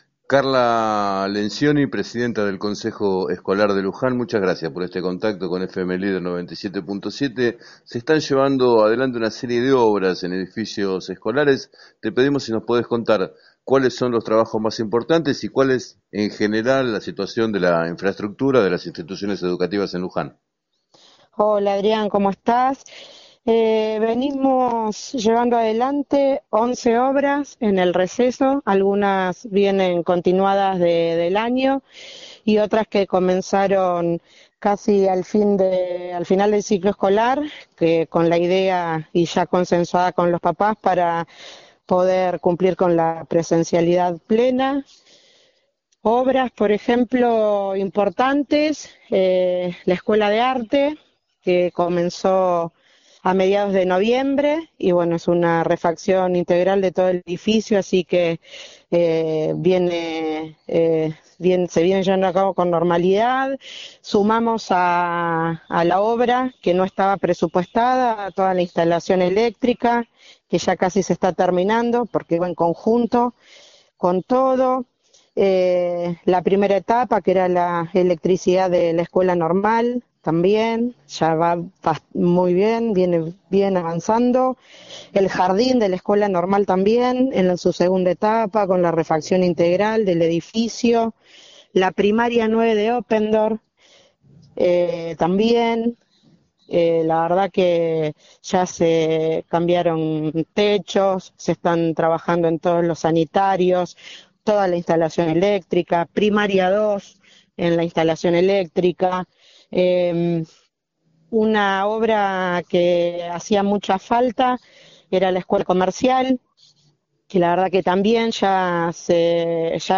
En declaraciones al programa “7 a 9” de FM Líder 97.7, la presidenta del Consejo Escolar, Carla Lencioni, detalló las reformas que se están realizando y destacó que trabajan con el objetivo de que en el próximo ciclo lectivo no haya ninguna institución con riesgo de inicio de clases.